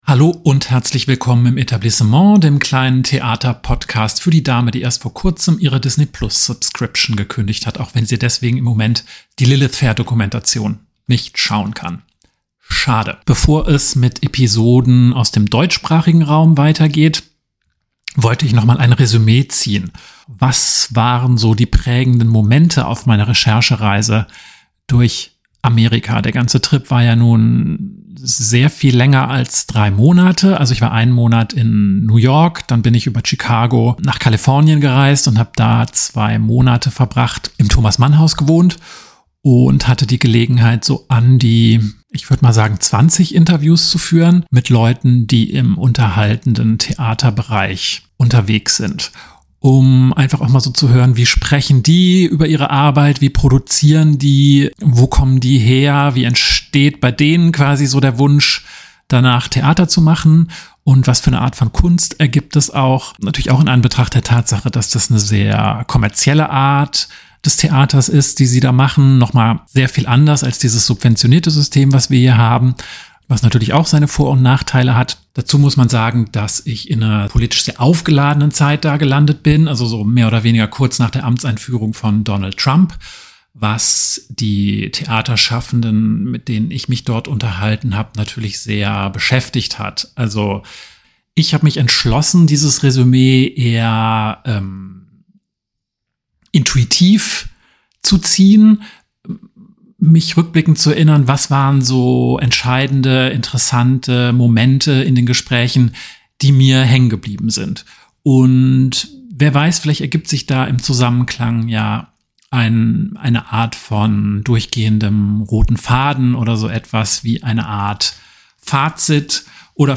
Beschreibung vor 6 Monaten Finally zurück aus den USA, wo ich mich dank eines Thomas Mann-Fellowships, im Bereich des amerikanischen Bühnen-Entertainments umsehen durfte. Um diese Recherche und die Gespräche, die in New York, Chicago und Kalifornien stattgefunden haben, Revue passieren zu lassen, hier der erste Teil eines zweiteiligen Rückblicks.